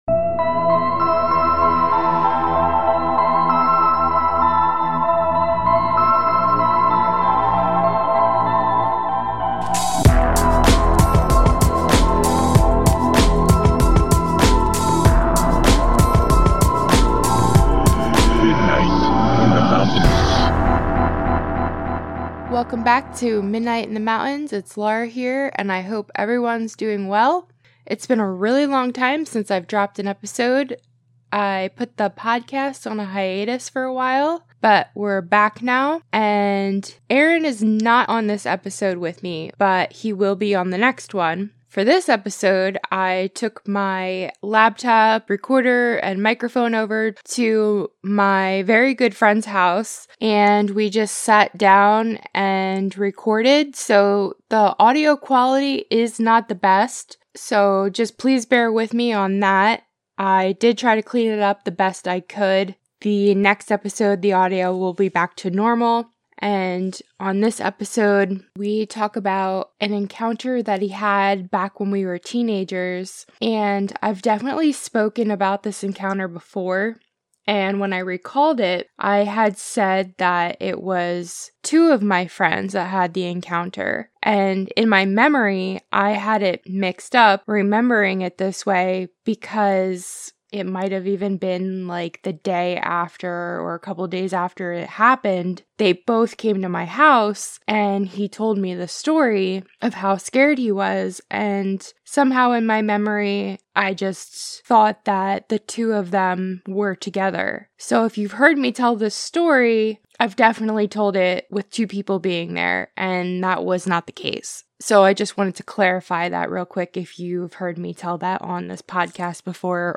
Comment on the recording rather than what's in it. They call him up to discuss the encounter. They discuss similarities, what the creature looked like and possible theories of where these creatures may have come from.